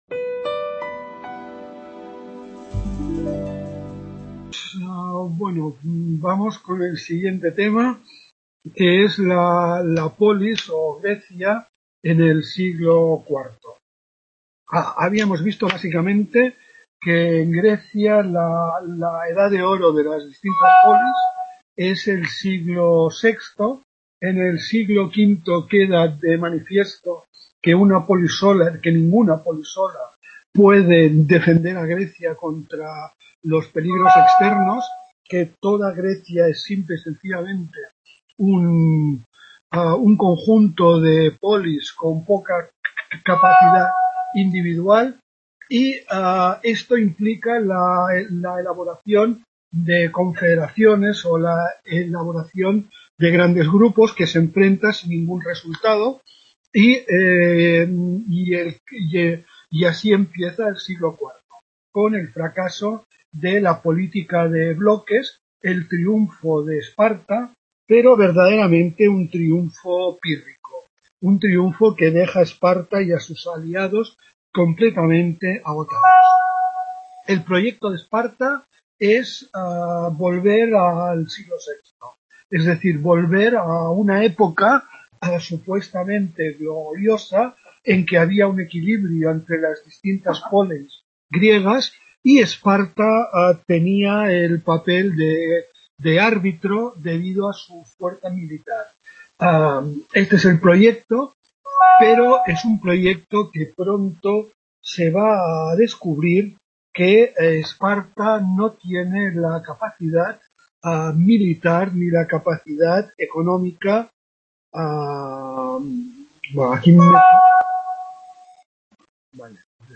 Tutoria